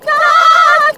SCREAM 8  -R.wav